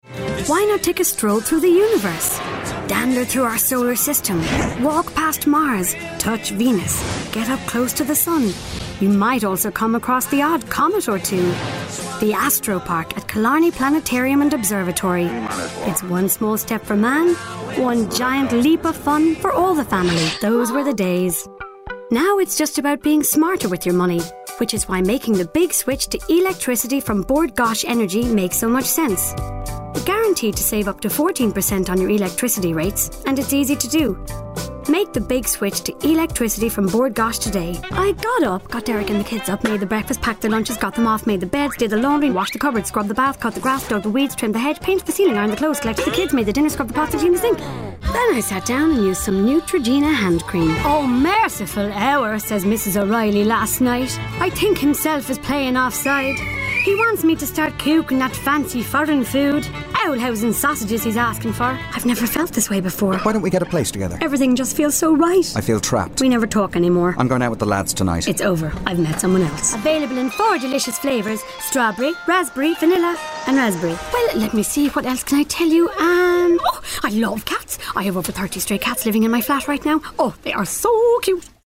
Female
Home Studio Setup
Mic: Rode NT1- A, Zoom H4n Pro
30s/40s, 40s/50s
Irish Neutral